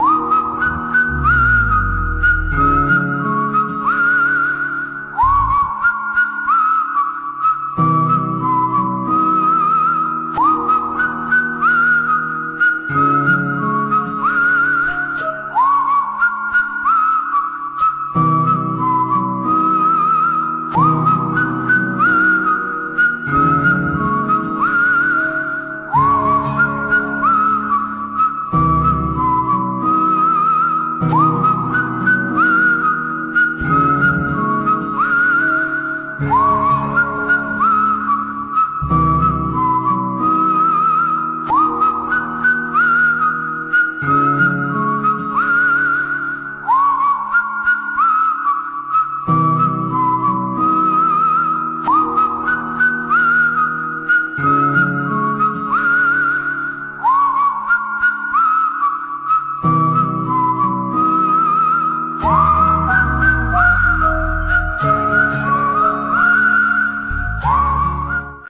الکترونیک و بلوز